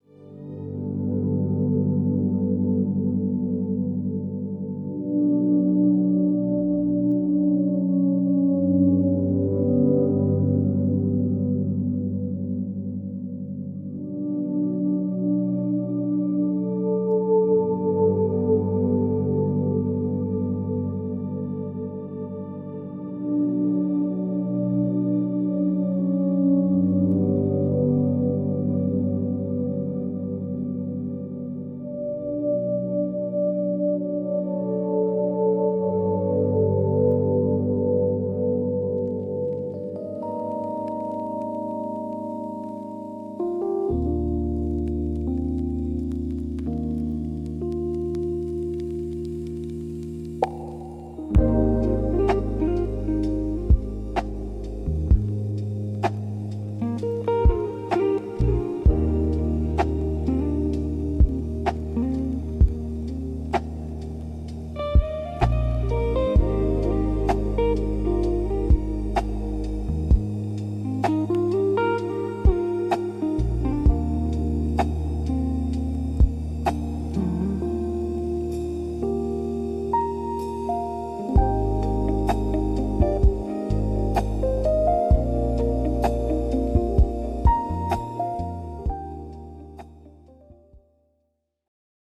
The SoundRoom Instrumental & Atmosphere Sample